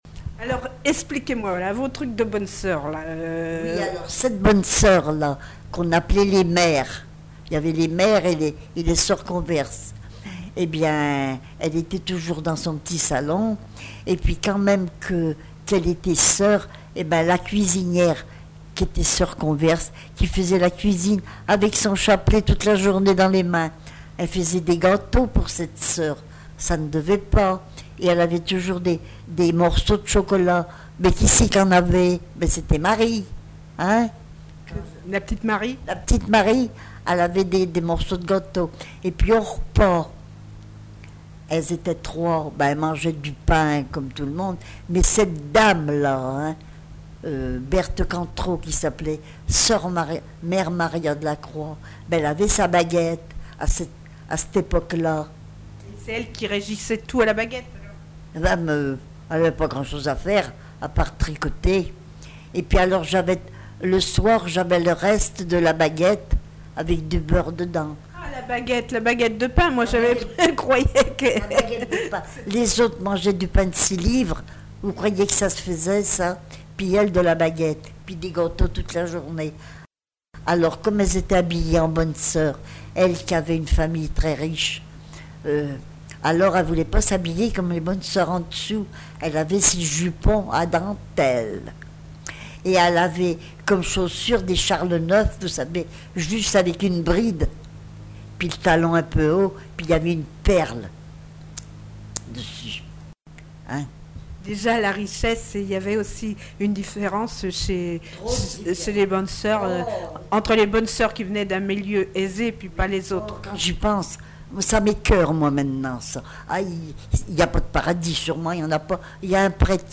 témoignages sur le vécu de l'interviewée
Catégorie Témoignage